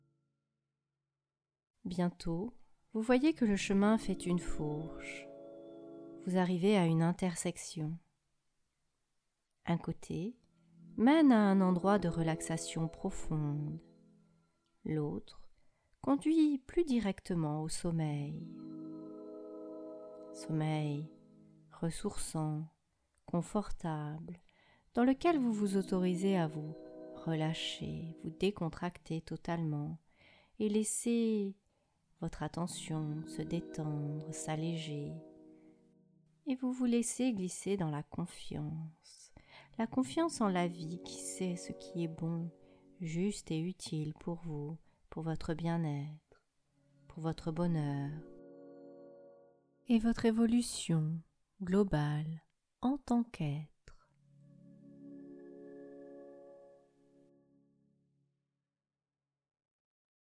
Le fond sonore est un arrangement à partir :